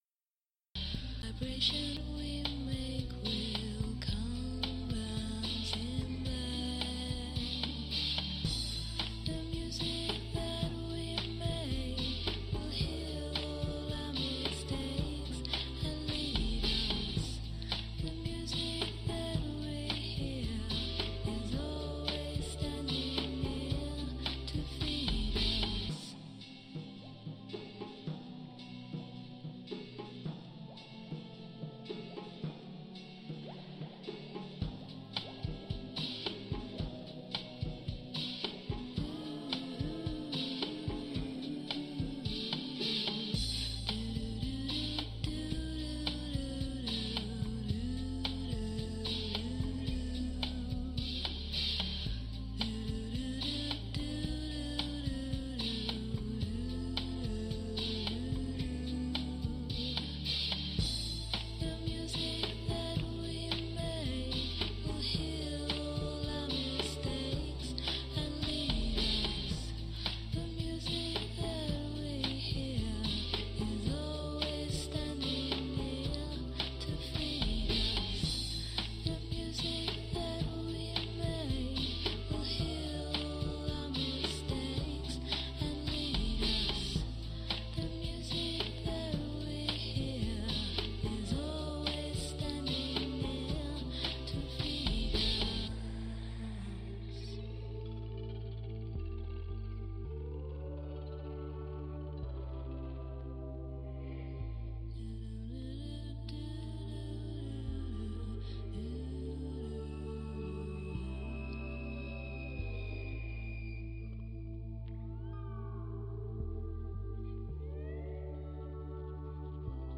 retro mixtape 2014_0907.mp3